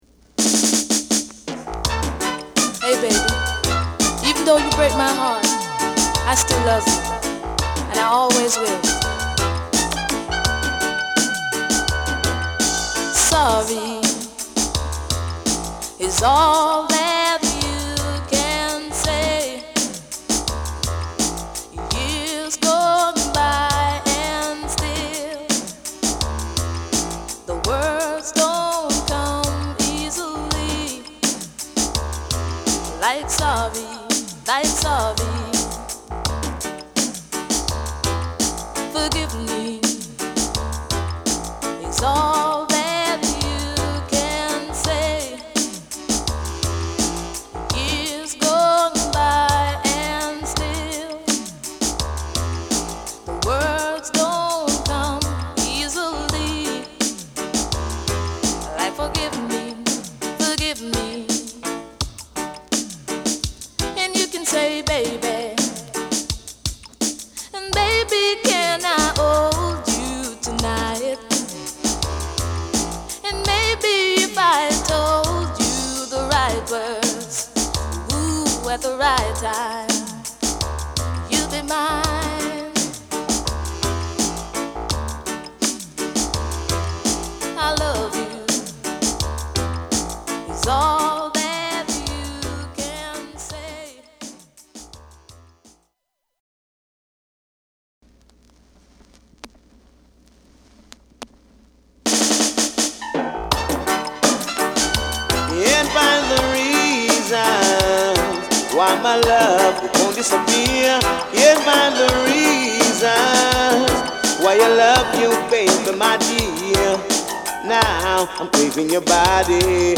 プレス・ノイズ有り（JA盤、Reggaeのプロダクション特性とご理解お願い致します）。